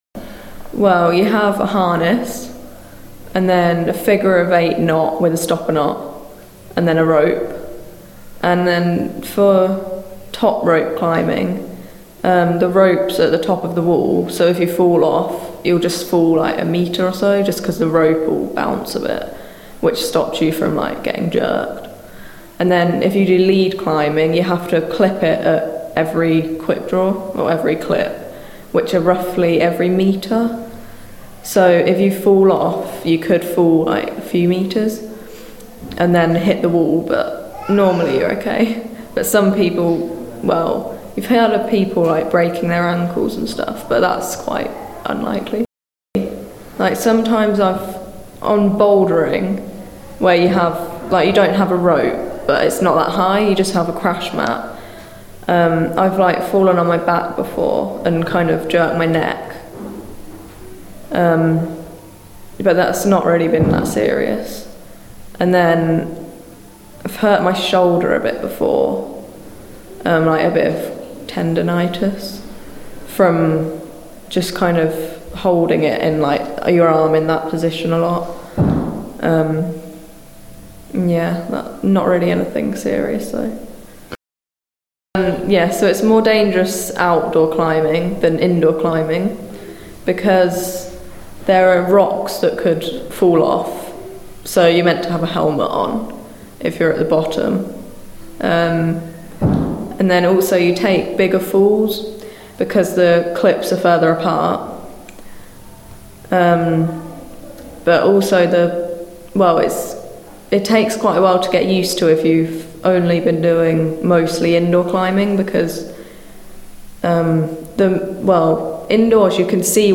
Sjanger: Rhythmic Soul